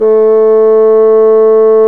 Index of /90_sSampleCDs/Roland L-CDX-03 Disk 1/WND_Bassoons/WND_Bassoon 2
WND BASSOO0D.wav